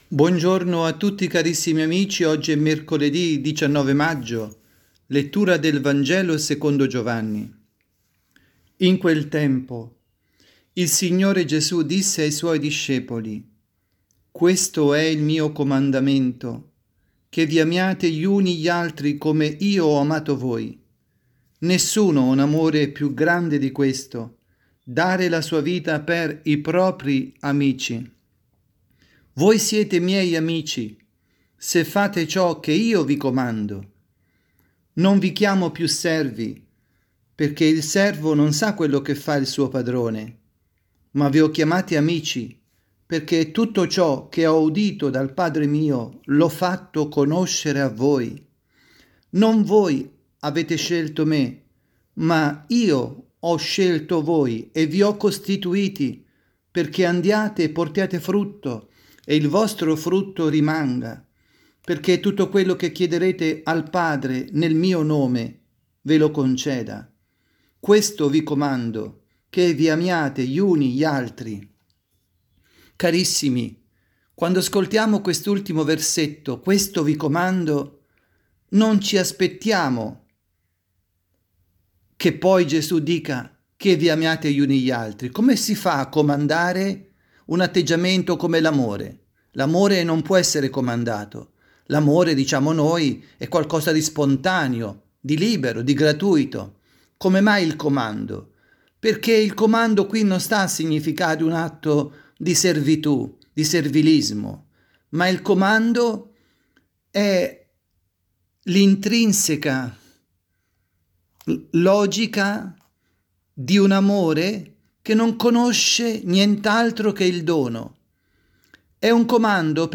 Catechesi
dalla Parrocchia Santa Rita – Milano